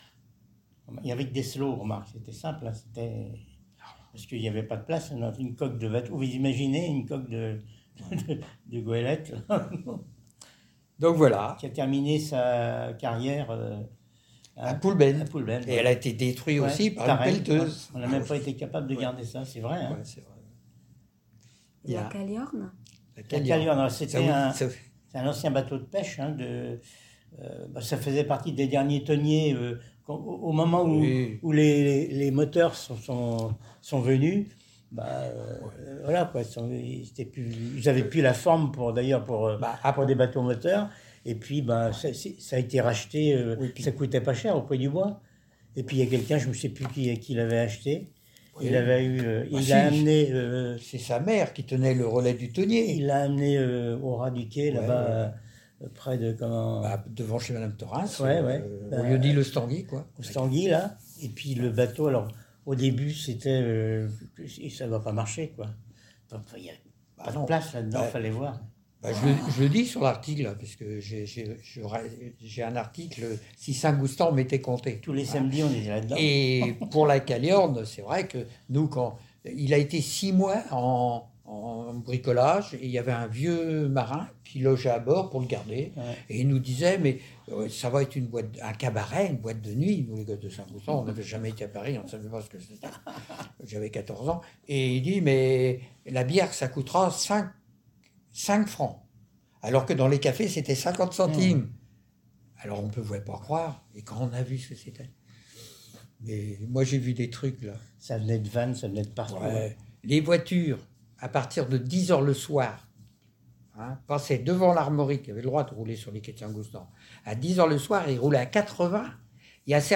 Témoignages audio